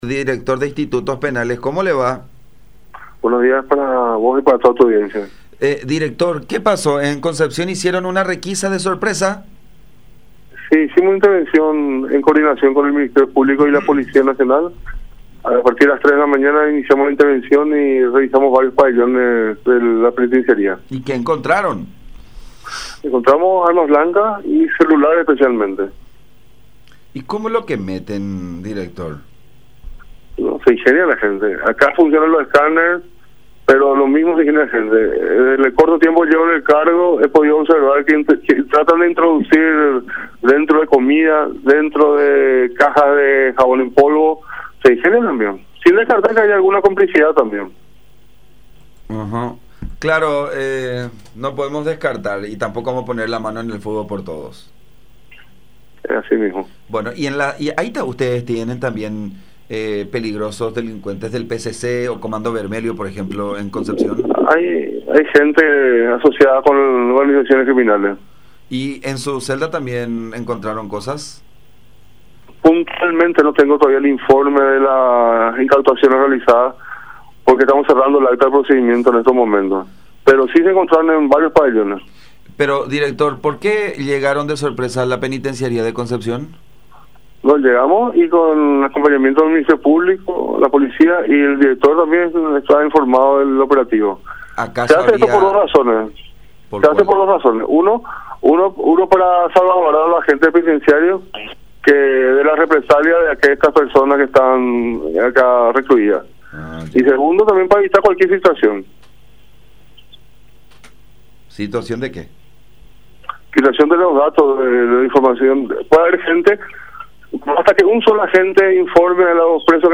El objetivo de estos procedimientos es evitar “situaciones complicadas” entre los presos, según explicó Carlos Figueredo, director de Institutos Penales, en diálogo con La Unión.